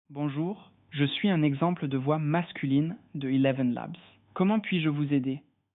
Example of the voices available in our catalog
voix-elevenlabs.wav